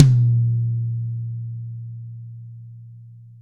Index of /90_sSampleCDs/Best Service - Real Mega Drums VOL-1/Partition G/DRY KIT 1 GM